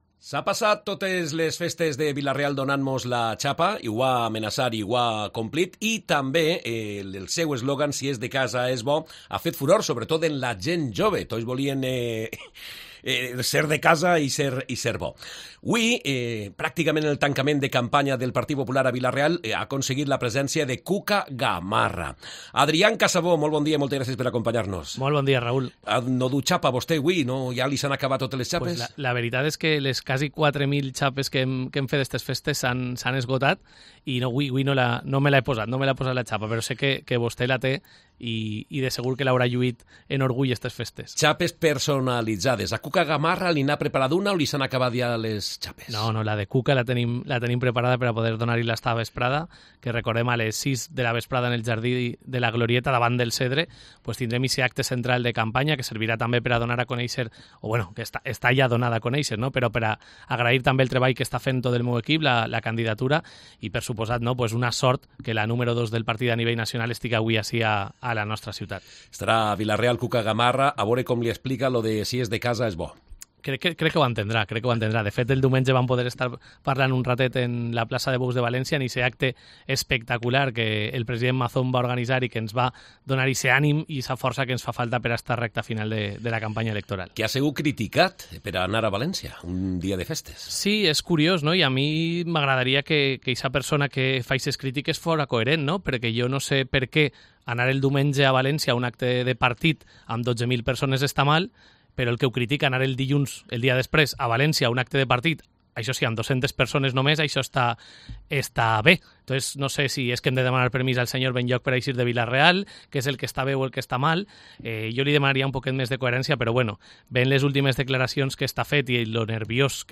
Ha dado cuenta de su programa y principales propuestas en esta entrevista.